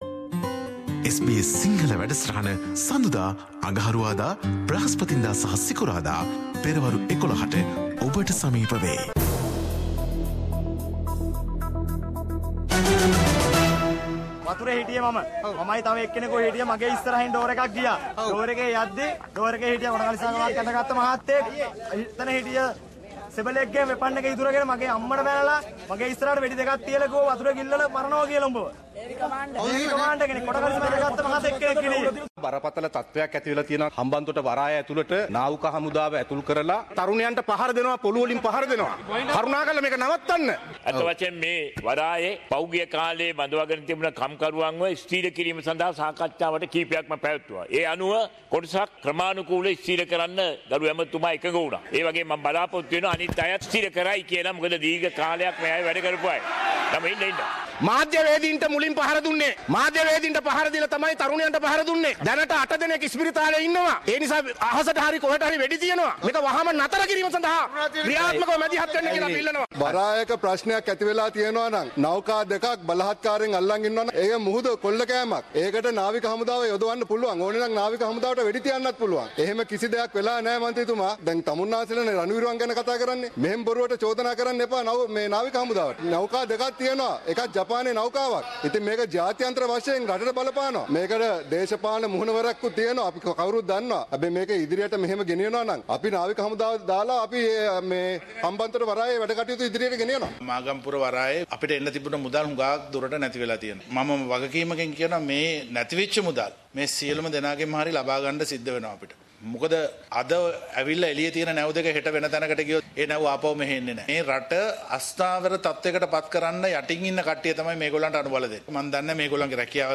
reports from Sri Lanka,